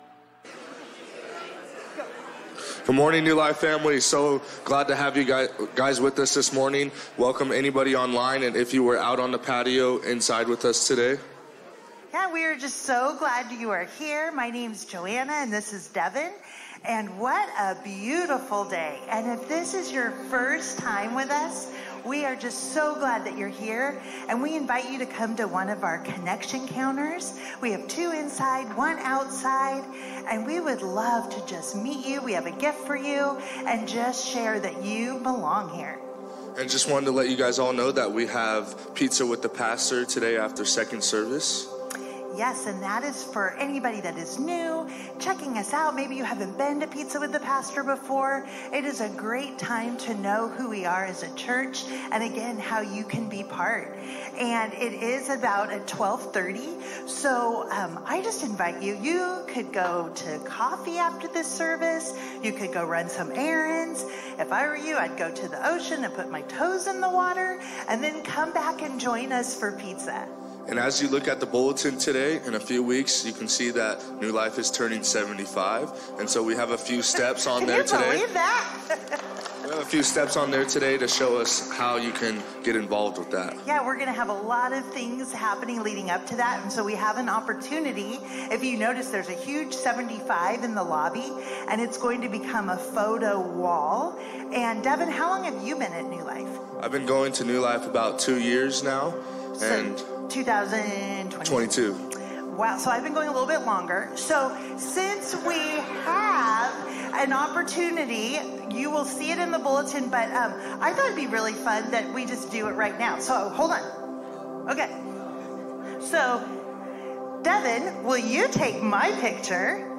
A message from the series "No Longer Strangers."